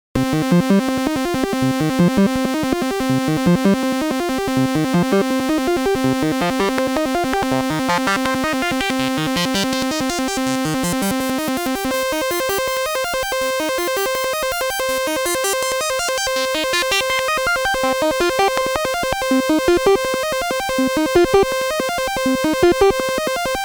The famous chip is a mixture of digital and analogue technology with phase accumulated oscillators and analogue multimode NMOS filter.